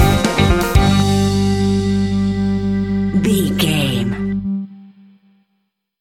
Aeolian/Minor
latin
uptempo
drums
bass guitar
percussion
brass
fender rhodes